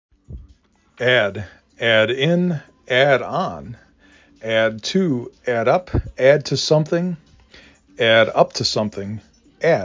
3 Letters, 1 Syllable
2 Phonemes
a d